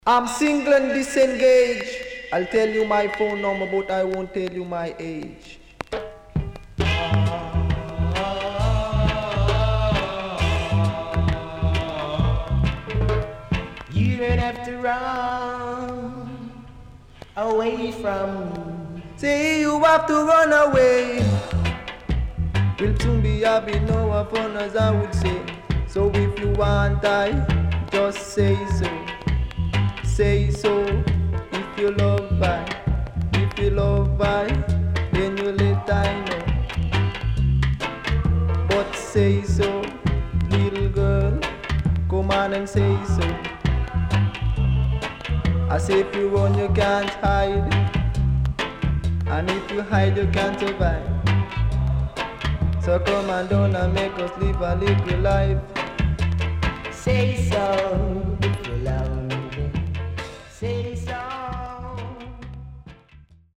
Deejay